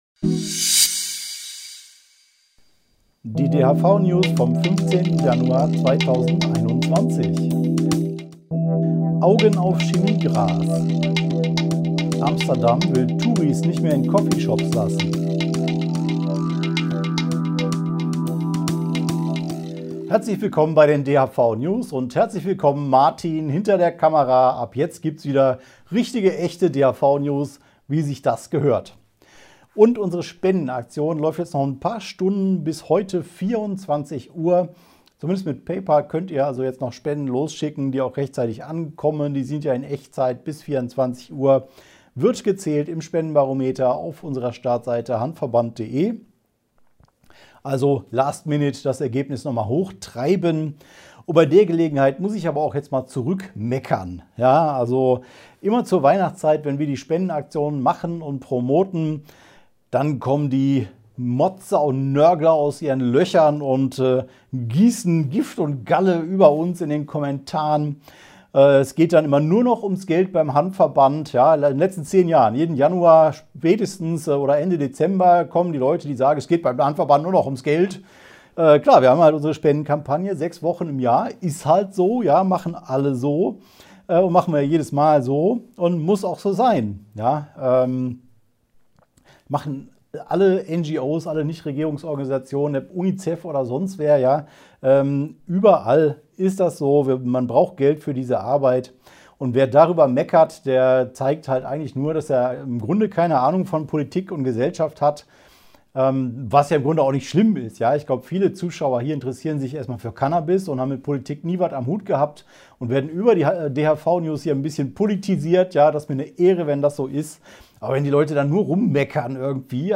| DHV-Video-News #277 Die Hanfverband-Videonews vom 15.01.2021 Die Tonspur der Sendung steht als Audio-Podcast am Ende dieser Nachricht zum downloaden oder direkt hören zur Verfügung.